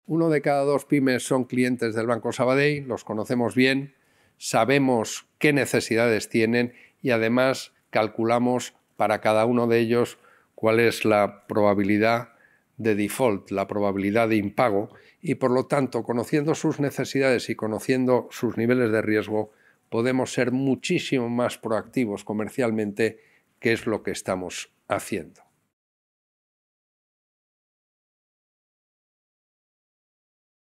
Material audiovisual de la rueda de prensa